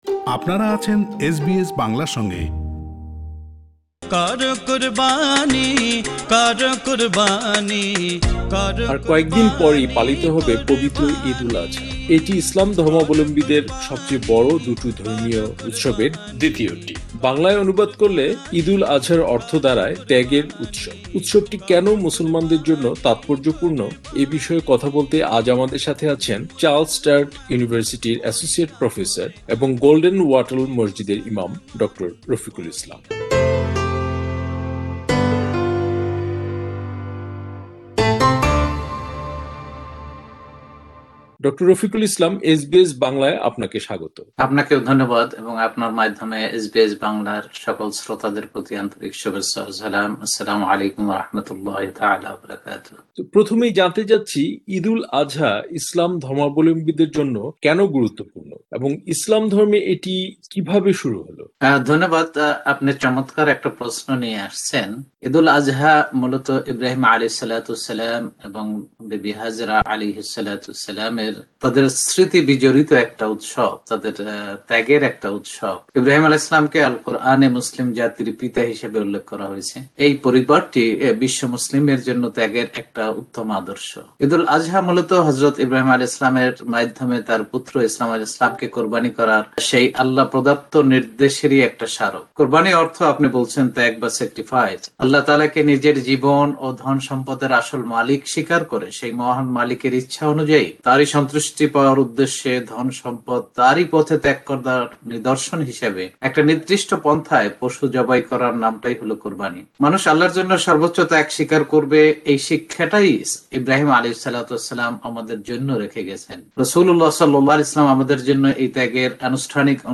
পবিত্র ঈদ উল আজহার তাৎপর্য নিয়ে এসবিএস বাংলার সাথে কথা বলেছেন
পুরো সাক্ষাৎকারটি